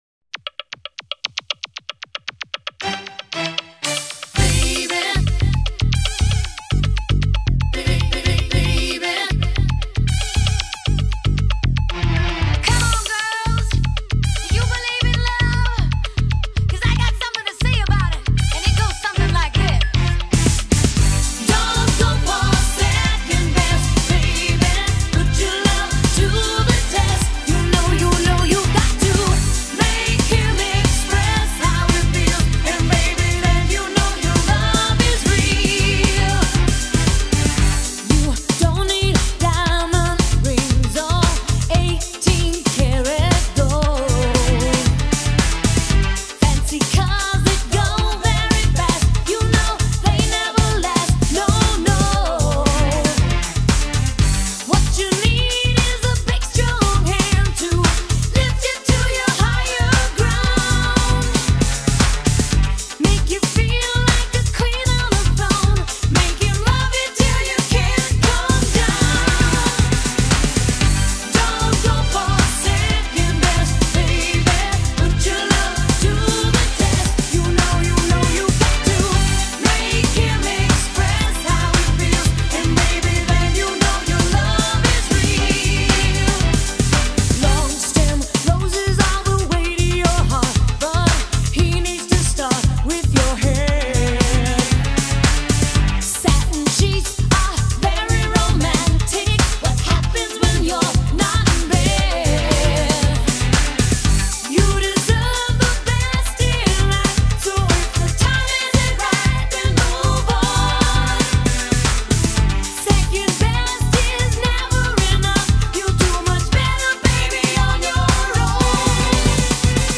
Pop, Live